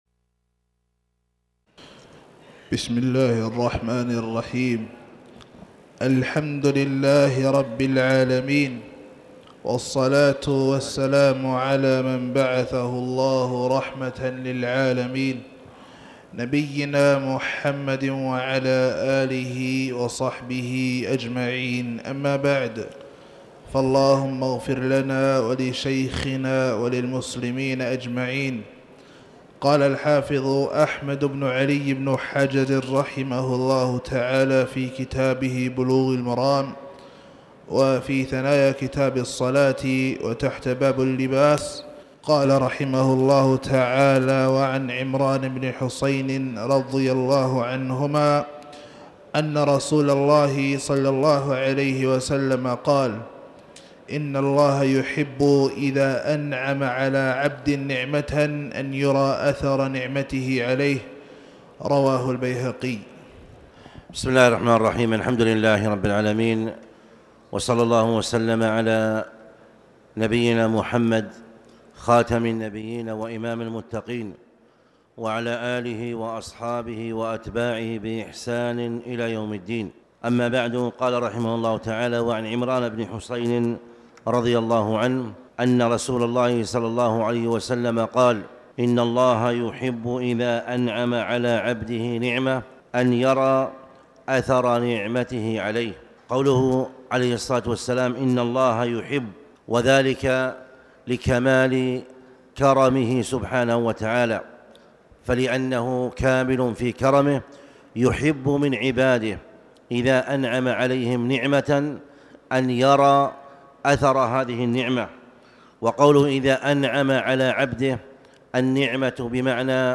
تاريخ النشر ١٩ ربيع الثاني ١٤٣٩ هـ المكان: المسجد الحرام الشيخ